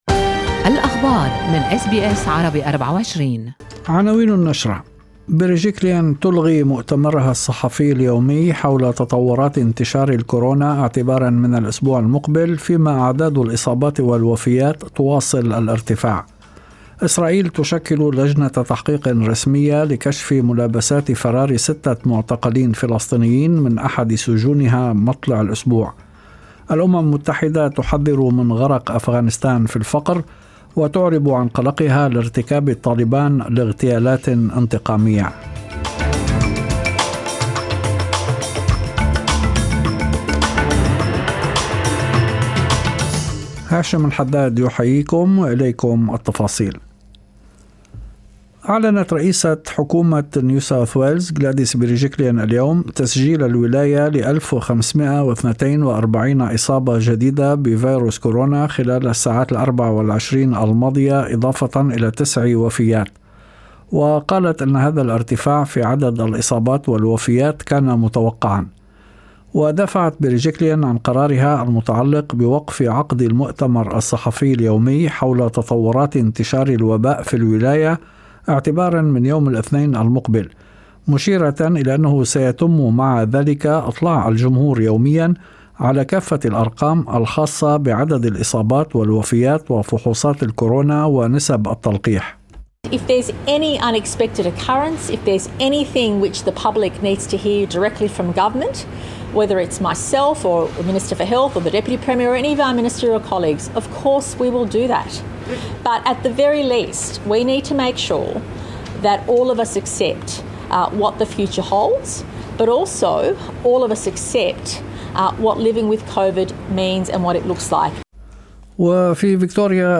نشرة أخبار المساء10/9/2021